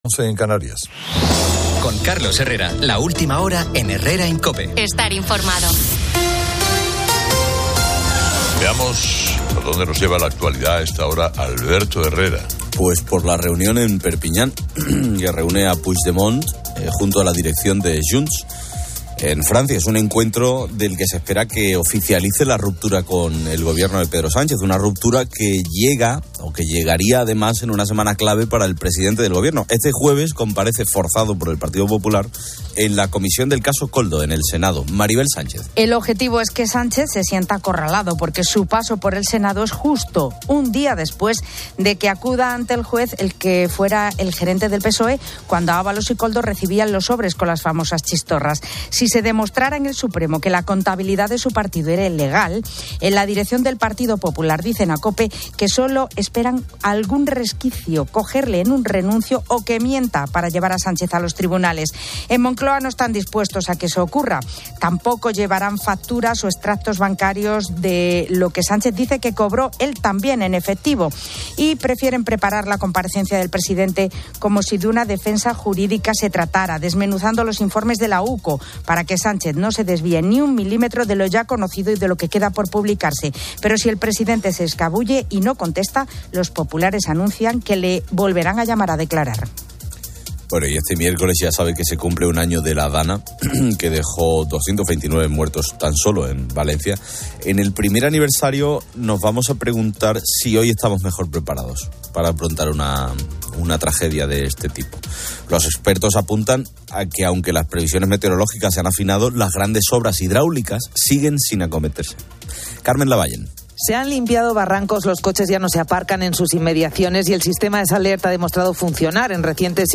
Carlos Herrera informa sobre eventos actuales. Se celebra una reunión de Puigdemont en Perpiñán que busca oficializar la ruptura con el Gobierno. Pedro Sánchez comparece en el Senado por el Caso Koldo, y su partido enfrenta posibles problemas legales. En Valencia, un año después de la DANA, se cuestiona la preparación ante futuras tragedias, mientras las grandes obras hidráulicas siguen pendientes.